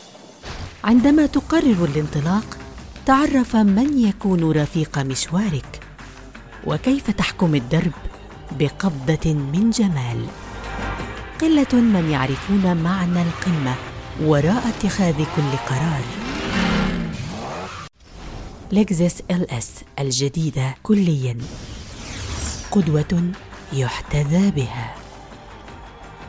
Automoción
Micrófono: Rode NT1-A
Estudio: Estudio casero con tratamiento profesional para una acústica óptima